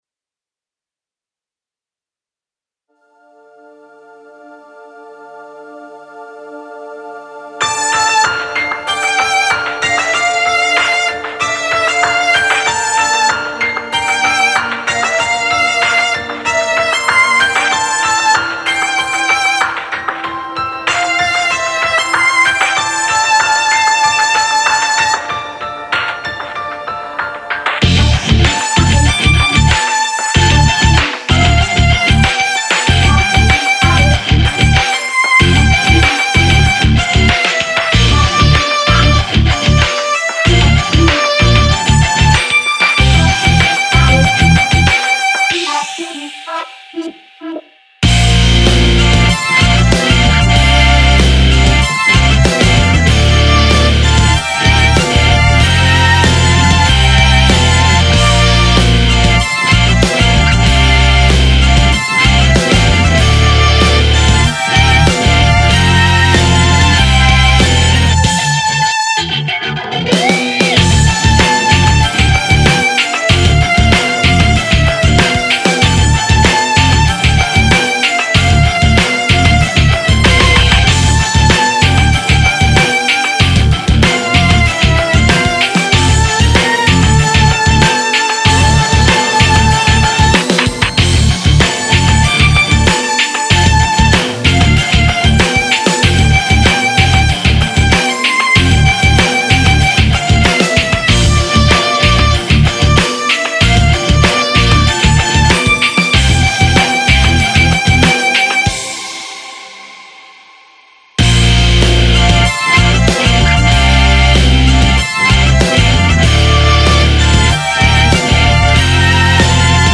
ボカロの歌はまだ入ってません。
2011年頃にボカロ用に制作していたラウド系のロック曲デモです。
メロディックなメロディーラインにラウドなギターが特徴のミクスチャーっぽい楽曲です。